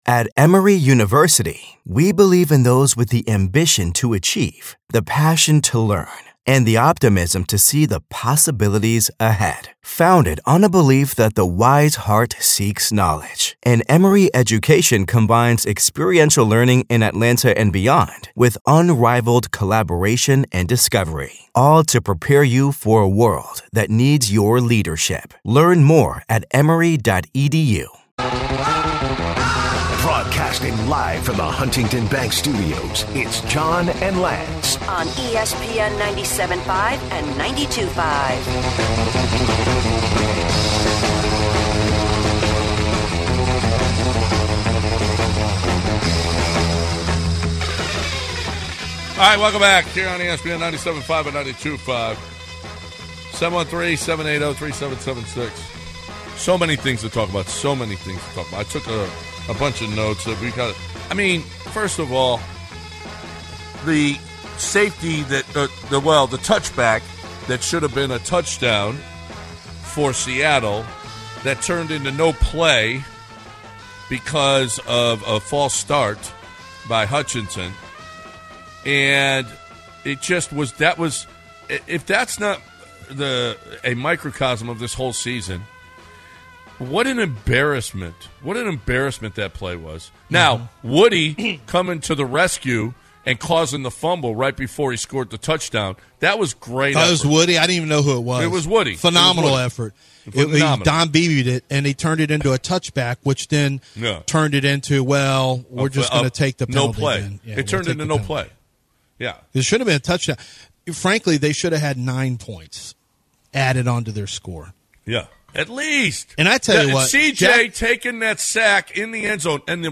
Callers chat Texans, MLB and Rockets